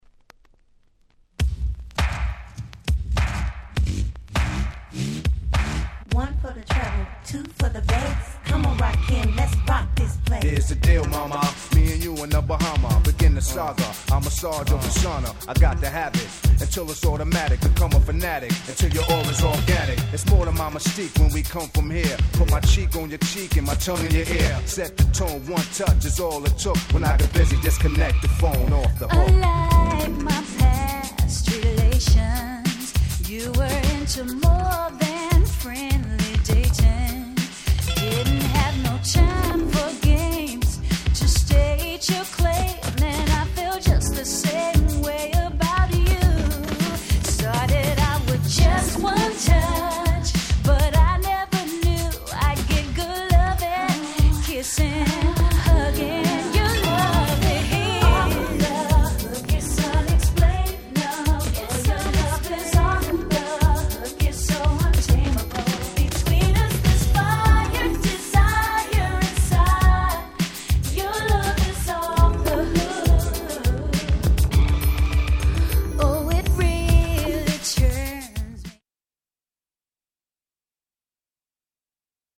Nice Hip Hop Soul !!